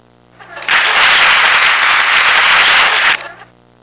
Quick Applause Sound Effect
Download a high-quality quick applause sound effect.
quick-applause.mp3